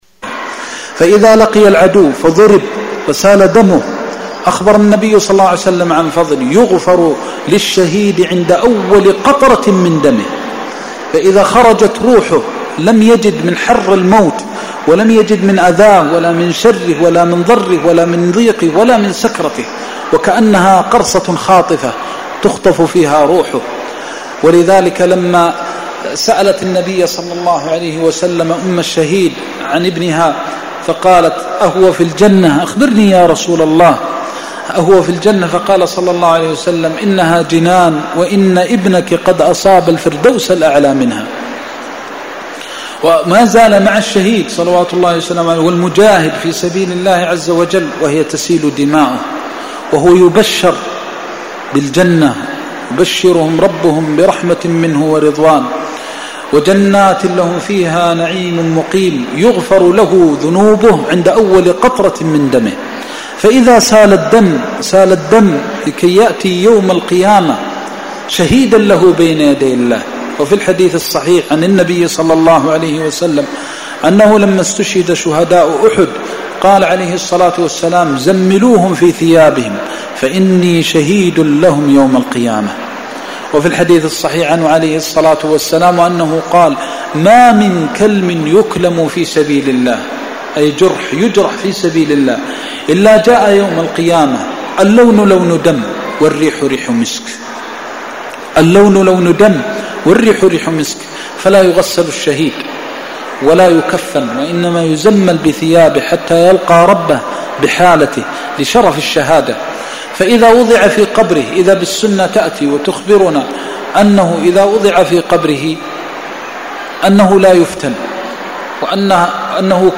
المكان: المسجد النبوي الشيخ: فضيلة الشيخ د. محمد بن محمد المختار فضيلة الشيخ د. محمد بن محمد المختار الجهاد (02)